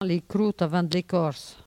Patois - archives
Catégorie Locution